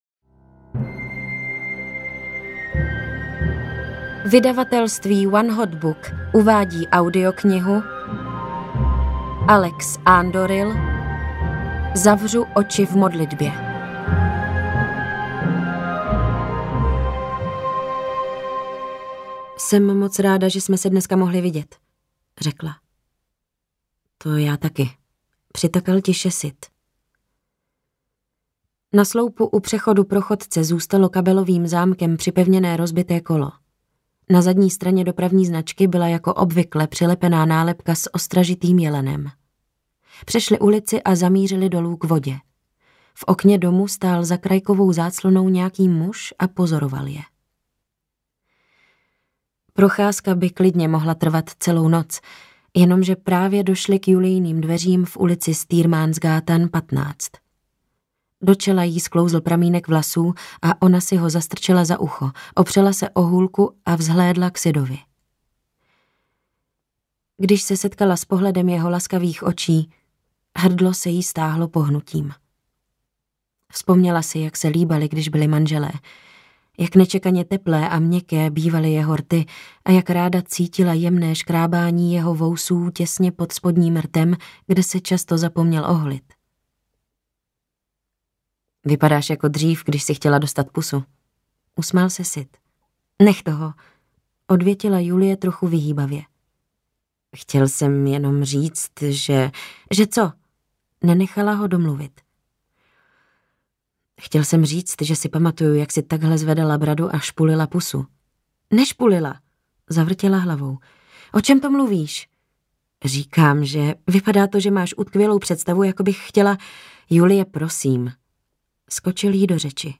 Zavřu oči v modlitbě audiokniha
Ukázka z knihy